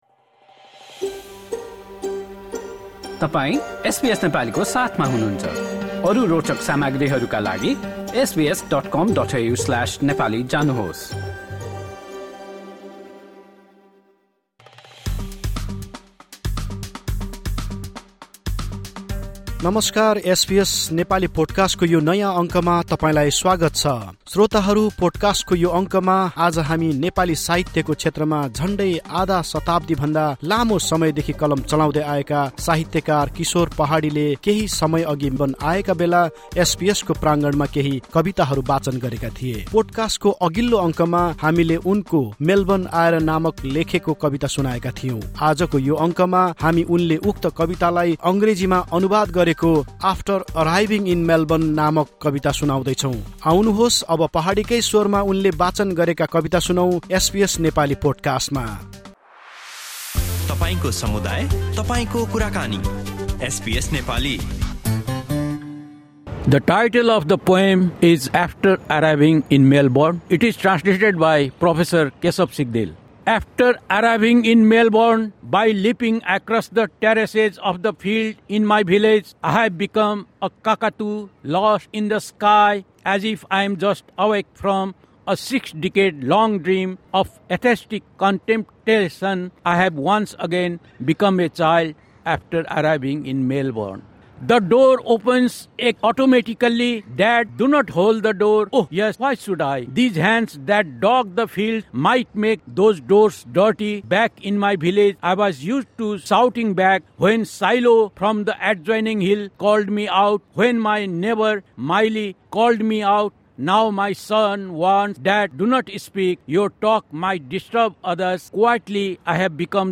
recitation in English
12 September 2025 at the Federation Square, Melbourne.